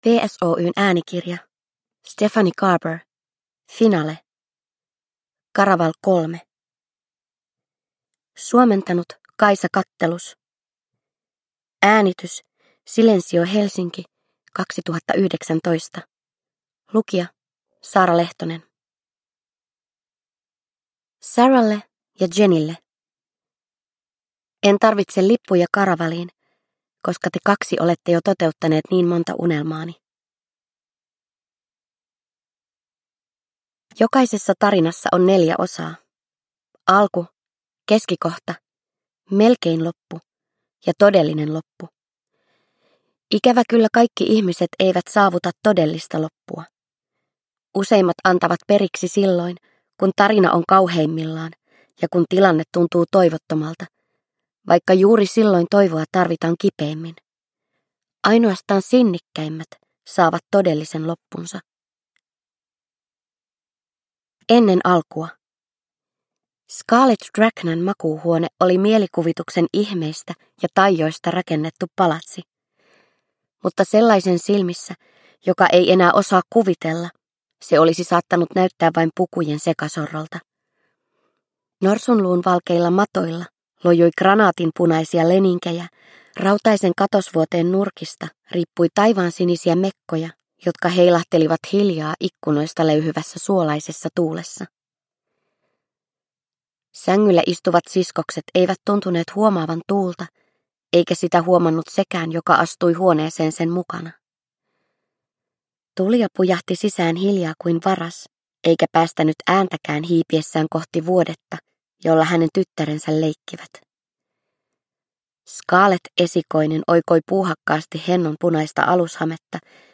Finale – Ljudbok – Laddas ner